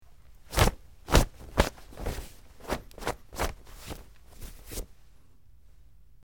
Cloth, Wipe
Brushing Off Clothes With Hands, X6